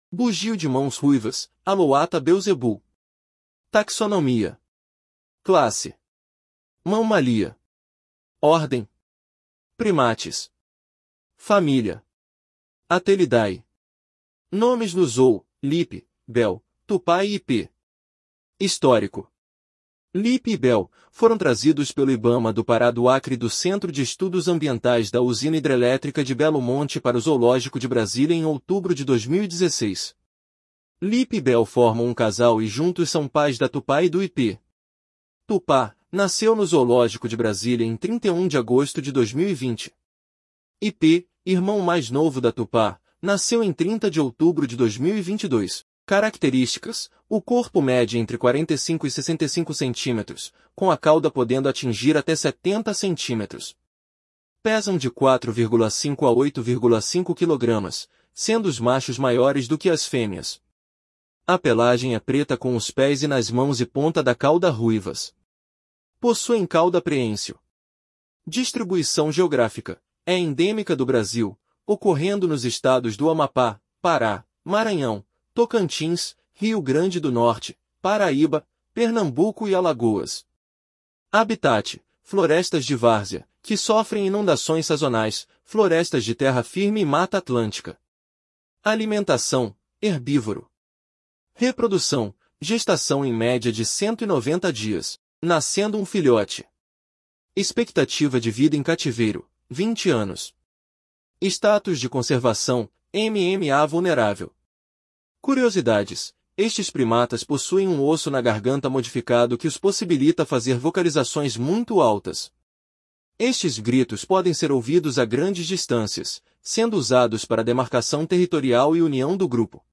Bugio-de-mãos-ruivas (Alouatta belzebul)
Curiosidades: Estes primatas possuem um osso na garganta modificado que os possibilita fazer vocalizações muito altas. Estes “gritos” podem ser ouvidos a grandes distâncias, sendo usados para demarcação territorial e união do grupo. Esse comportamento acontece principalmente ao amanhecer e entardecer, sendo o coro iniciado pelo macho alfa.